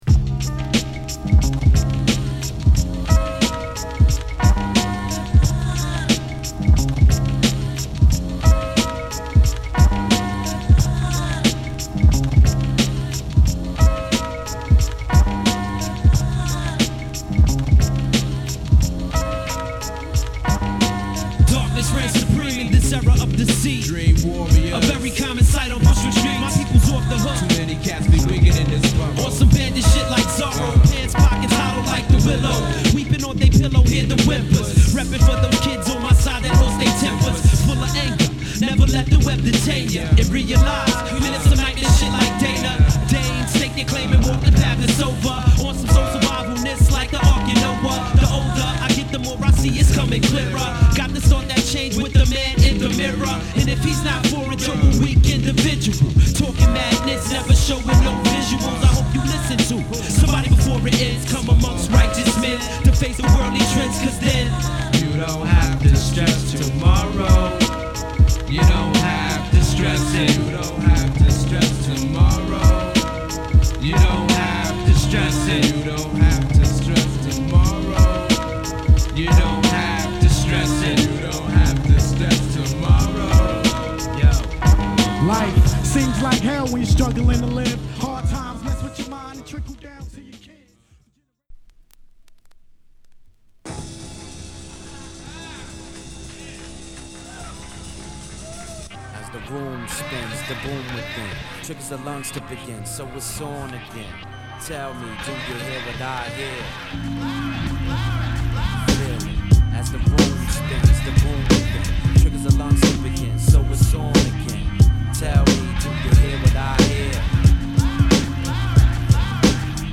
メロウHip Hop